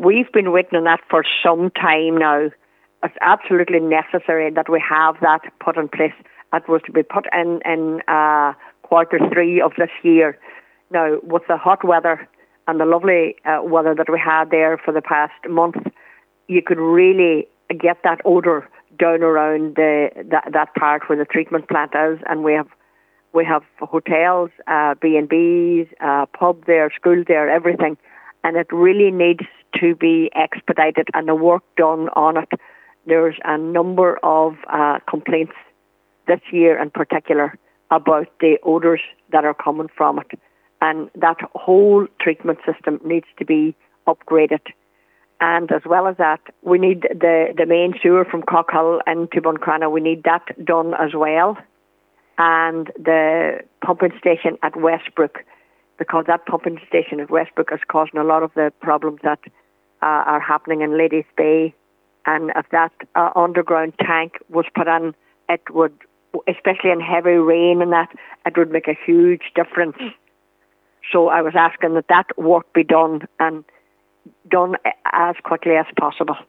Councillor Rena Donaghey says businesses are being impacted by the smell and that she says is unacceptable: